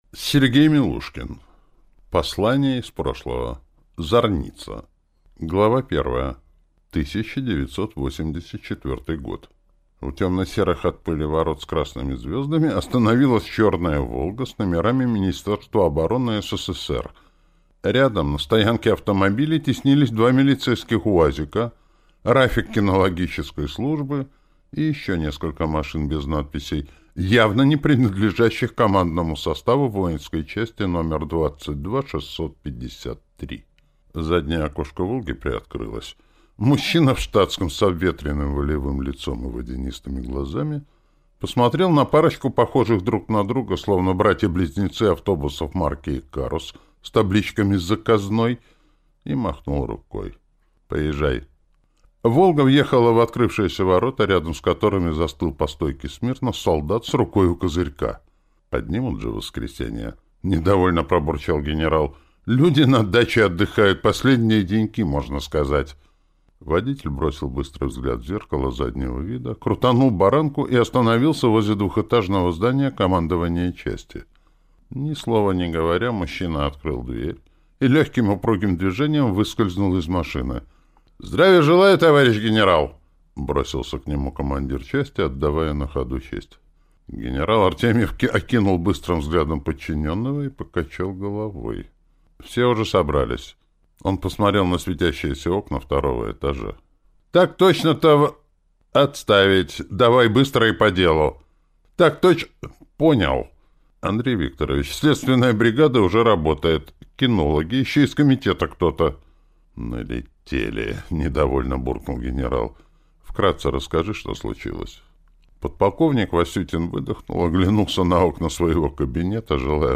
Аудиокнига Послание из прошлого. Зарница | Библиотека аудиокниг